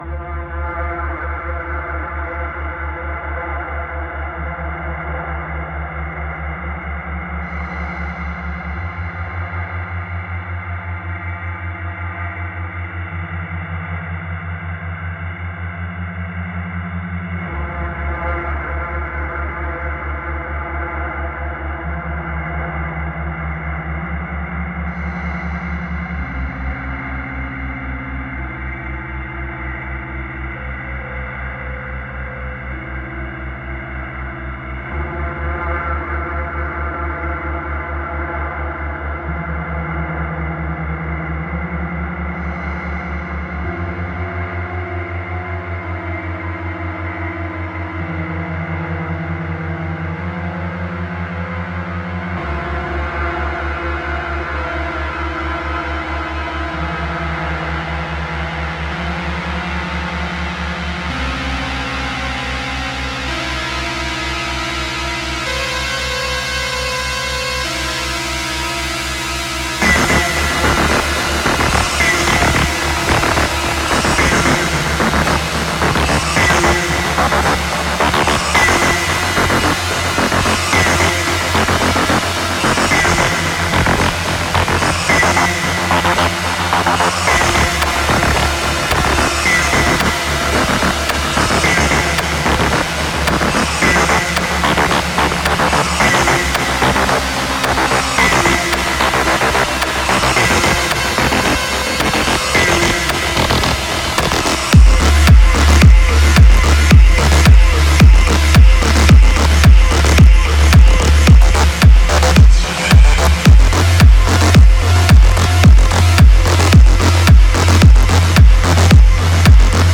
Genre: Witch House.